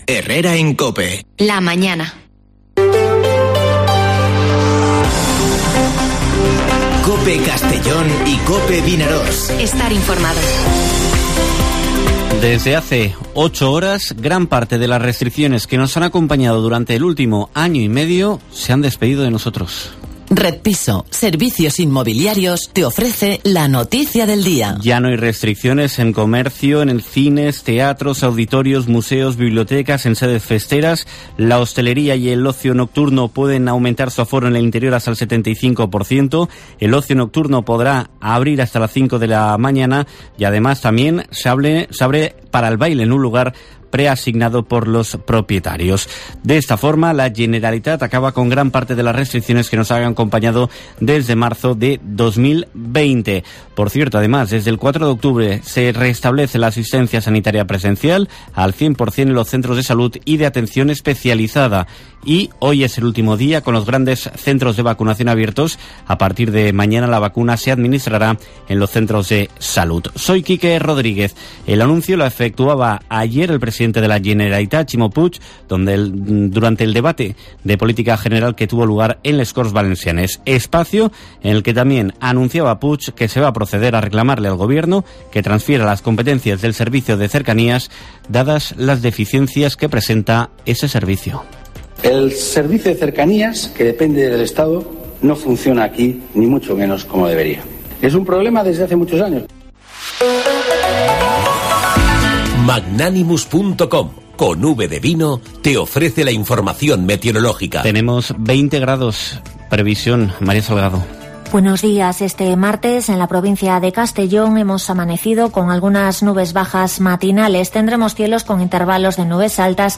Informativo Herrera en COPE en la provincia de Castellón (28/09/2021)